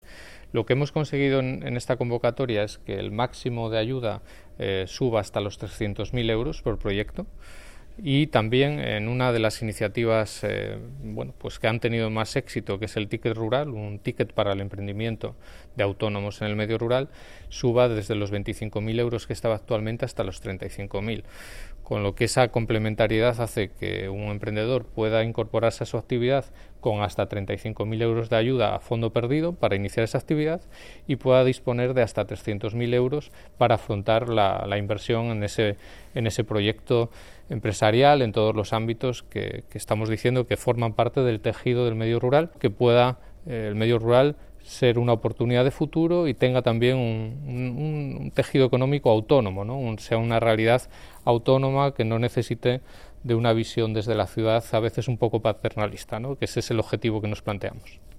Declaración del Consejero sobre las novedades de la convocatoria